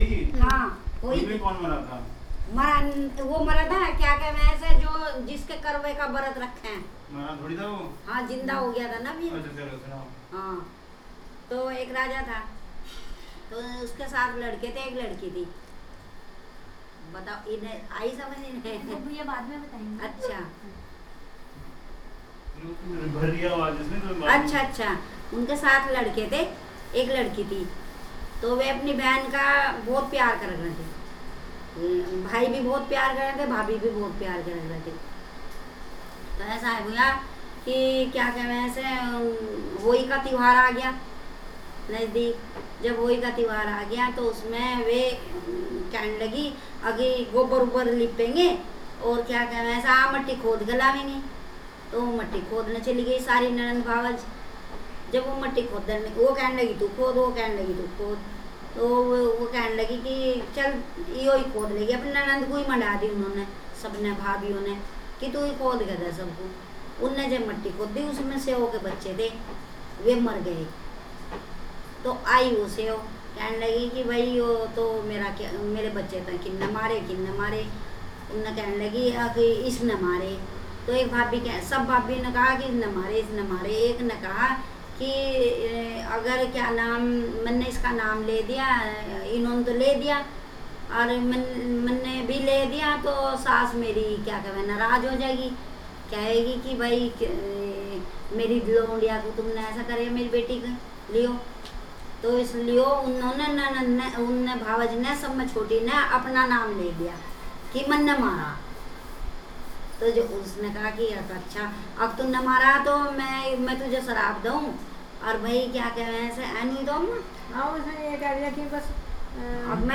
The image encapsulates aspects the story of Ahoi which is told by a senior woman to other women who come together.
The story being told in Hindi language at AZIMVTH Ashram is available as an audio file (.WAV format, 68.2 Mb, 6.45 minutes) below.
festivals-ahoi-ashtami-audio-story.wav